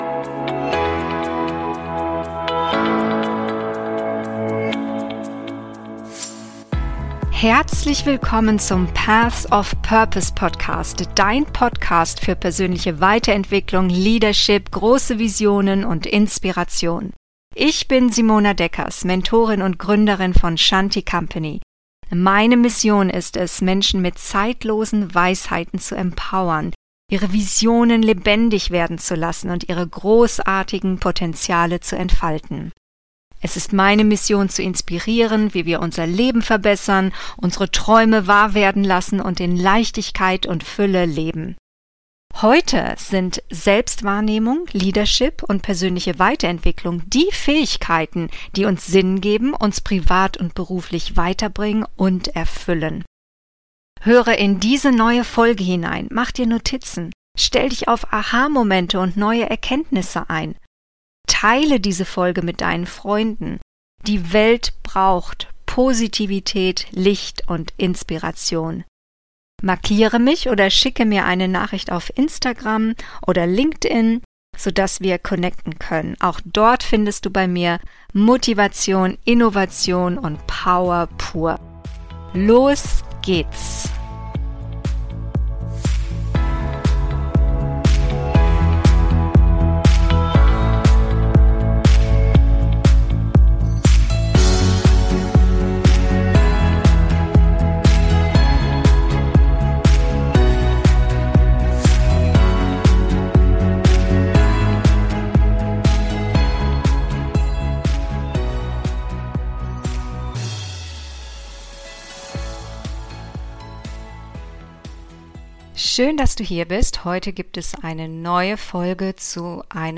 Purpose: was willst Du - wirklich? - Solofolge